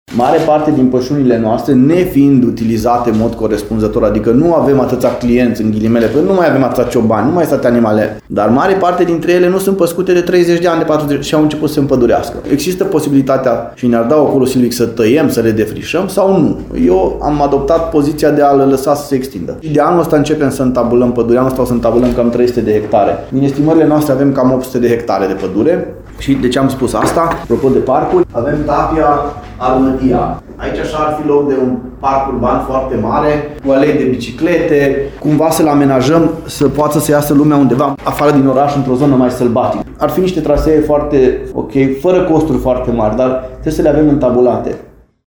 În zona menționată, administrația Lugojului deține sute de hectare de pășune, care, în mare parte s-au împădurit și au devenit o zonă sălbatică, spune primarul Lugojului, Claudiu Buciu.